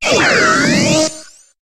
Cri de Motisma dans Pokémon HOME.